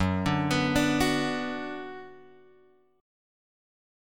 F#sus4#5 chord {2 5 x 4 3 2} chord